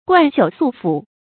貫朽粟腐 注音： ㄍㄨㄢˋ ㄒㄧㄨˇ ㄙㄨˋ ㄈㄨˇ 讀音讀法： 意思解釋： 穿錢的繩子朽斷，倉庫的糧食腐爛。形容富有之極。